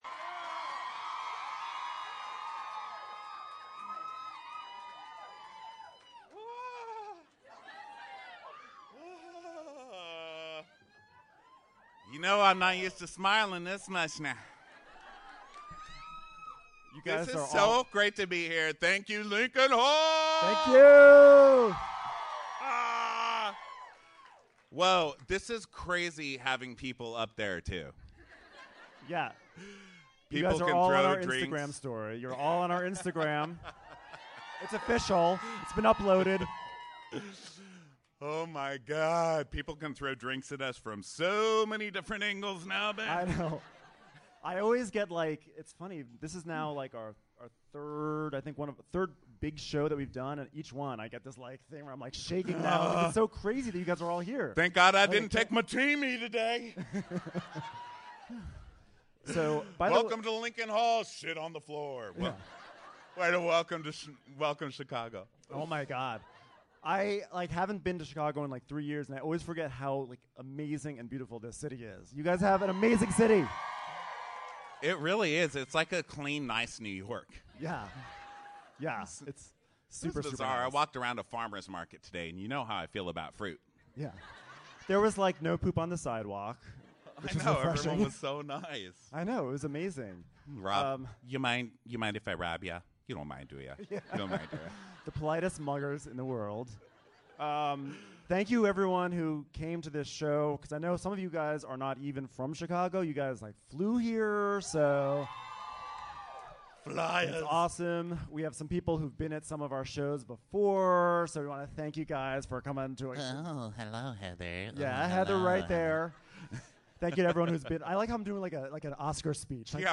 Chicago welcomed us right this week. We yelled, laughed screamed, and hugged like mad. Not to mention talked crazy poo about this week’s hilarious episode of Real Housewives of Orange Count.
Our girl LeeAnne Locken showed up for a little fun, so we dragged her onstage for an interview only she can give.